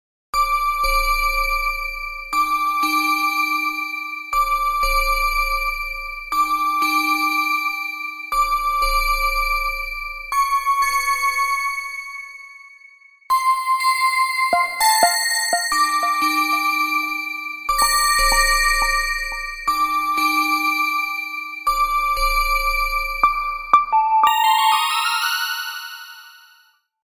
mp3 cell phone ringtone